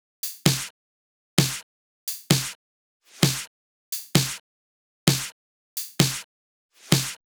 VDE 130BPM Change Drums 6.wav